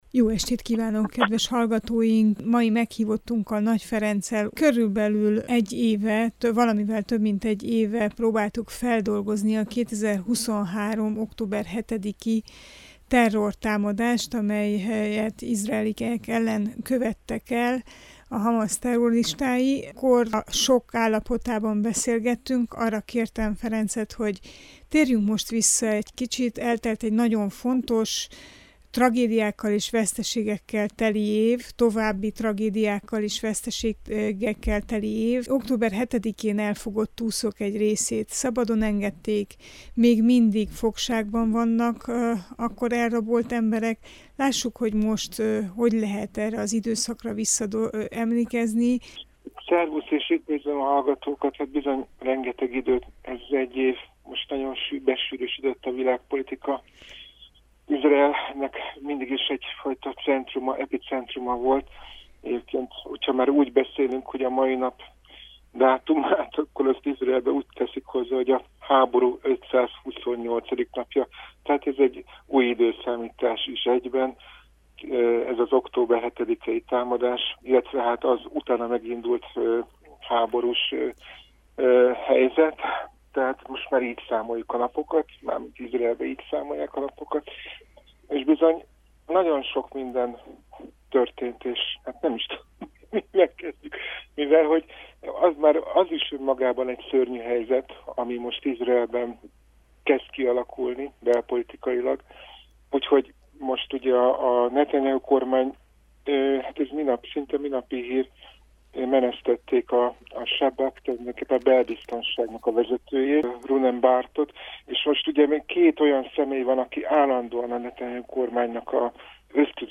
az Értsünk szót adásában beszélgettünk az izraeli és gázai tragédiák európai tanulságairól